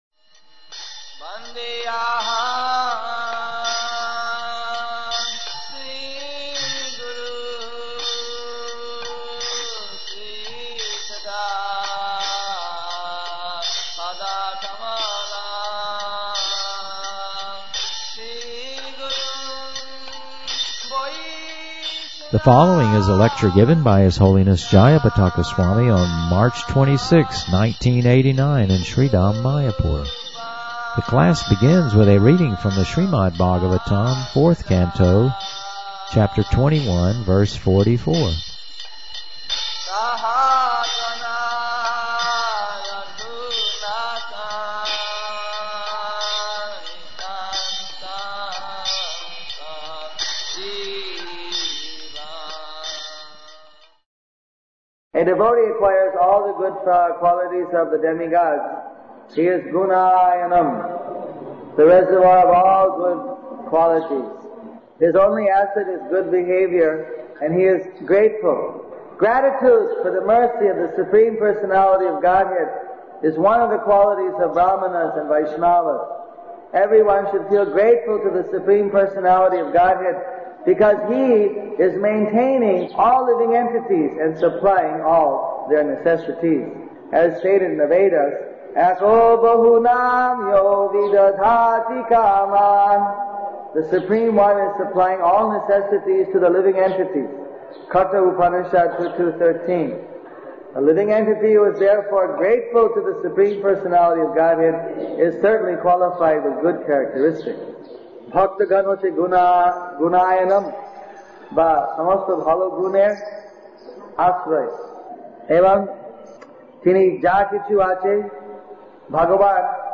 Srimad-Bhagavatam